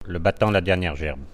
Langue Maraîchin
Locution ( parler, expression, langue,... )